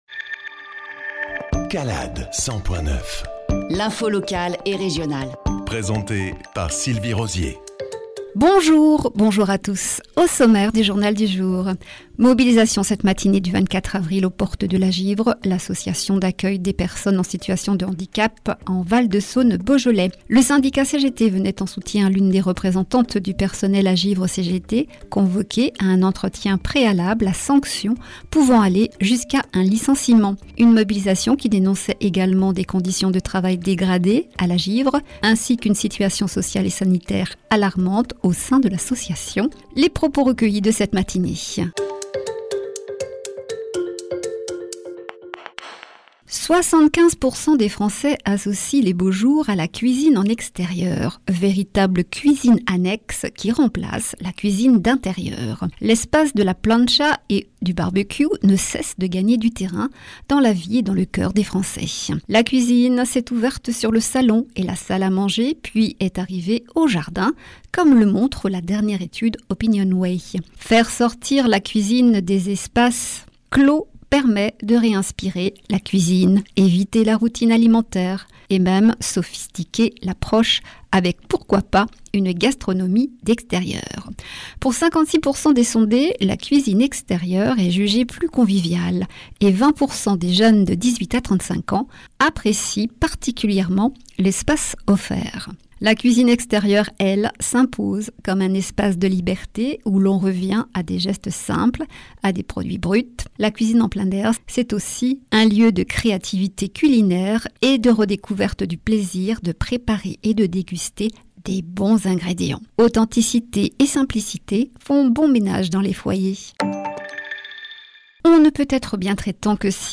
JOURNAL – 240425